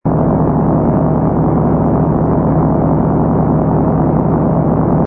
engine_ci_fighter_loop.wav